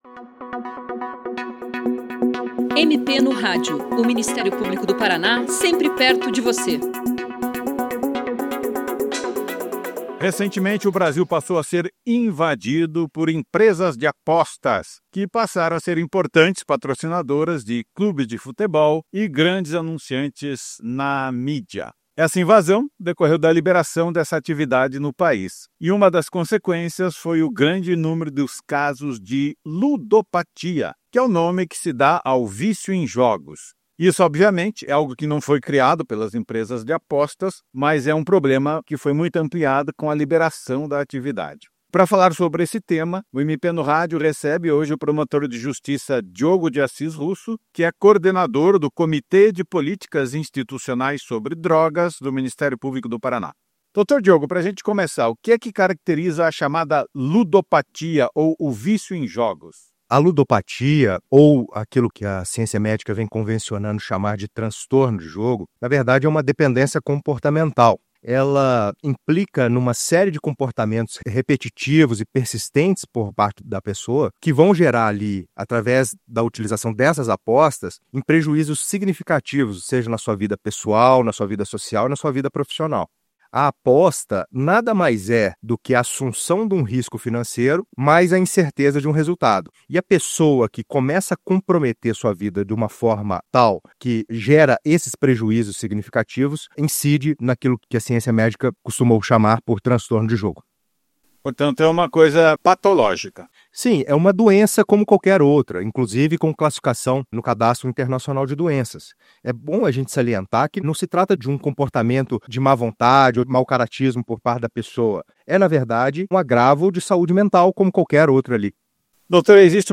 Ministério Público do Paraná trata do vício em jogos, a ludopatia. Ouça a entrevista com o promotor de Justiça Diogo de Assis Russo, coordenador do Comitê de Políticas Institucionais sobre Drogas do MP/PR
O MP no Rádio trata do vício em jogos, a ludopatia. O entrevistado é o promotor de Justiça Diogo de Assis Russo, coordenador do Comitê de Políticas Institucionais sobre Drogas do Ministério Público do Paraná, que explica quais as modalidades de jogo atualmente regularizadas no país, fala da atuação do Ministério Público nesta área e aponta caminhos que podem ser buscados por pessoas que estão com o problema de vício em jogos.